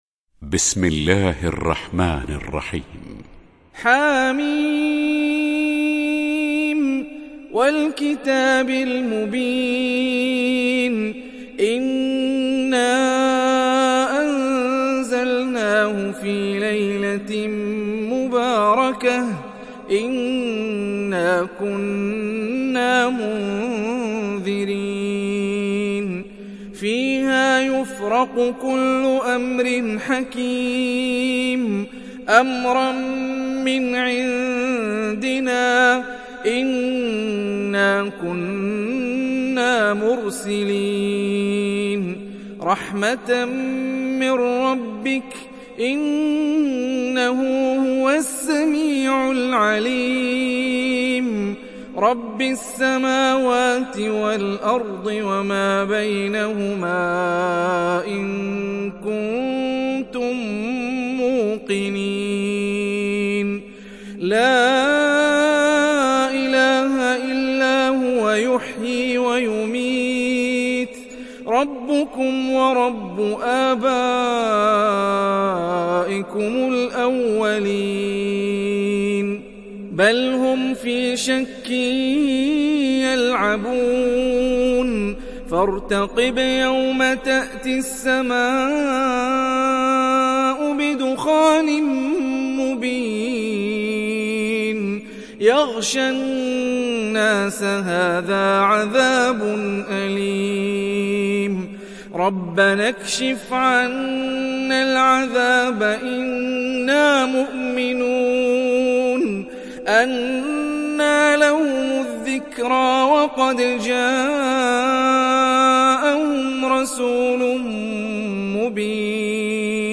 تحميل سورة الدخان mp3 بصوت هاني الرفاعي برواية حفص عن عاصم, تحميل استماع القرآن الكريم على الجوال mp3 كاملا بروابط مباشرة وسريعة